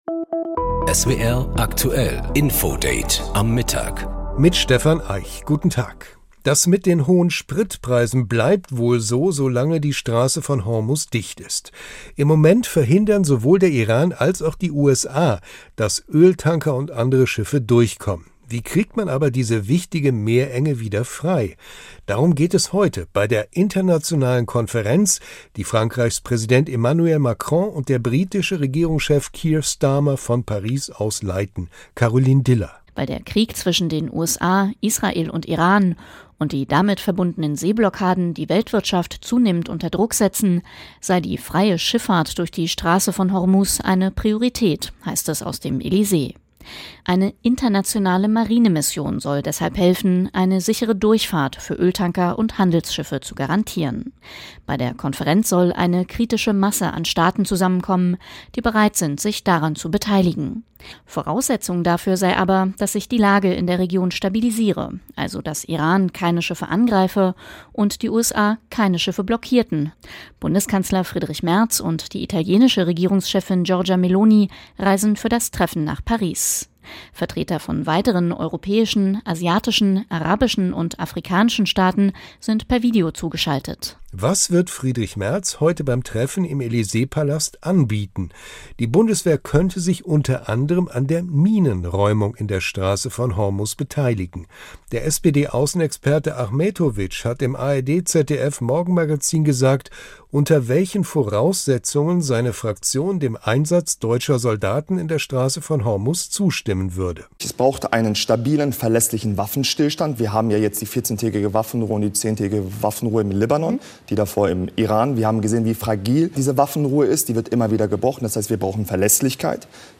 Im Gespräch mit SWR Aktuell